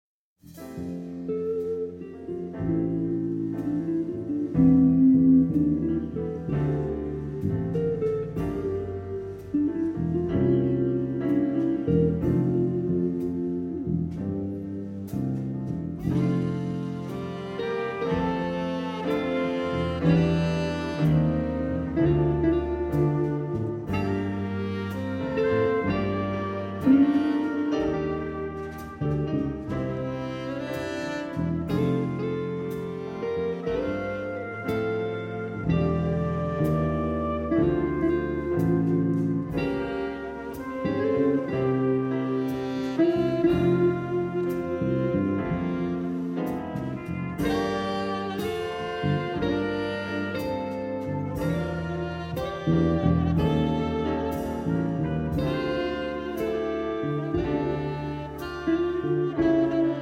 Symphonic Band
Wind Ensemble
Wind Symphony